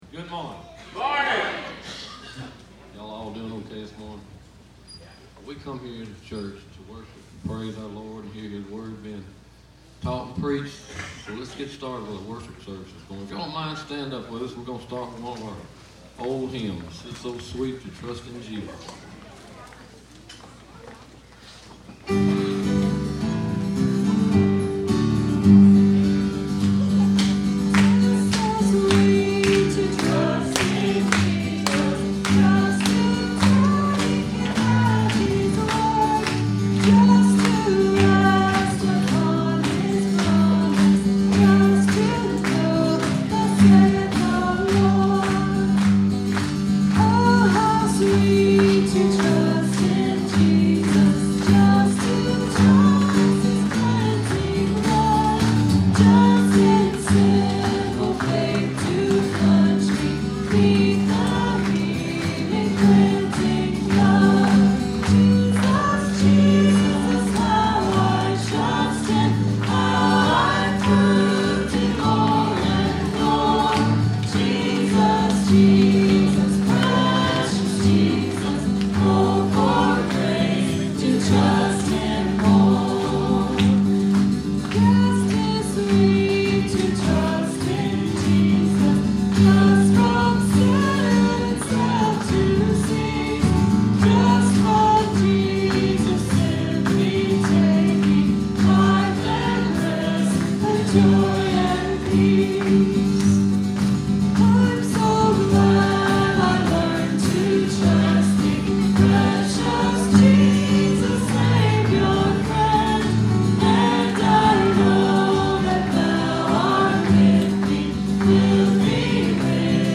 Listen to Lord Light the Fire - 04_17_16_Sermon.mp3